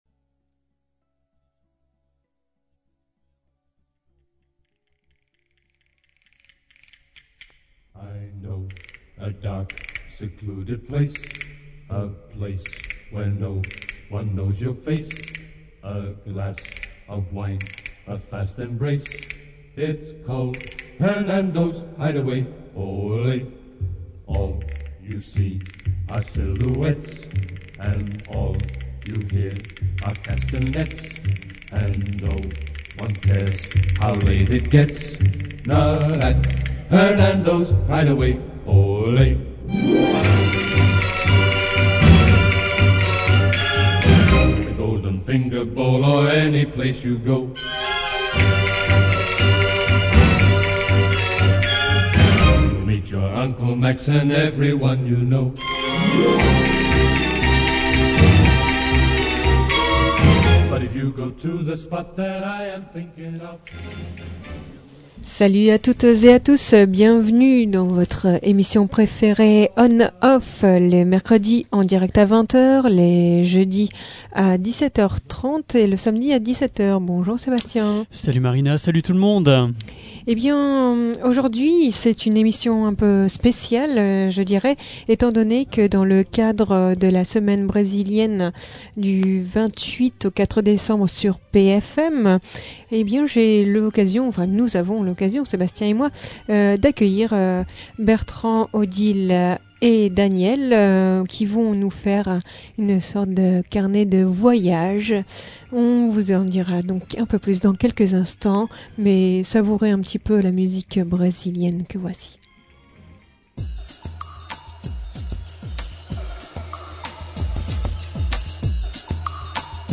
Ecoutez-les raconter leur expérience, laissez vous séduire par quelques musiques brésiliennes.